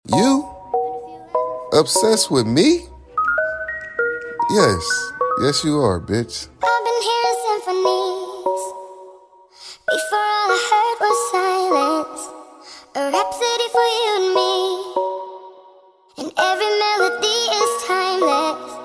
Hihihih sound effects free download